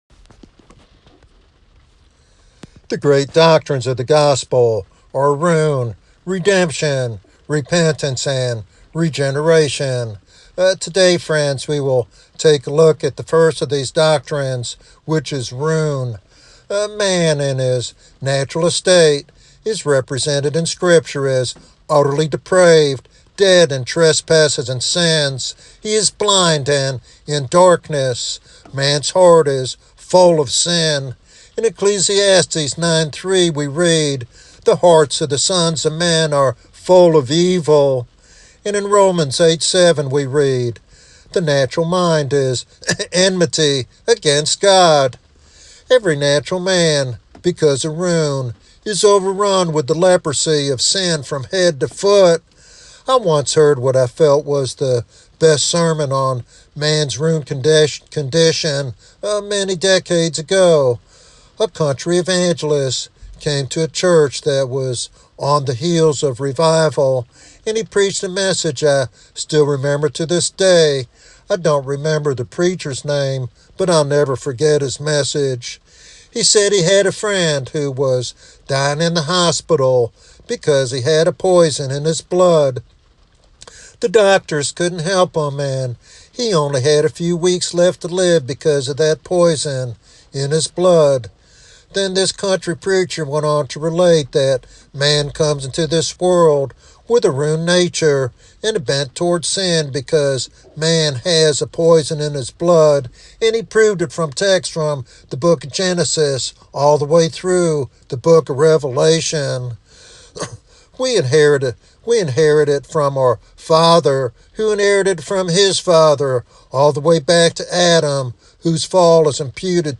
This message serves as a powerful reminder of the gospel's call to repentance and new life in Christ.
Sermon Outline